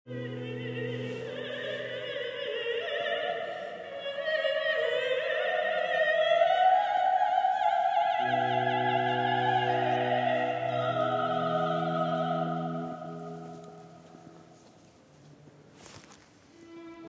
From the Cathedral practice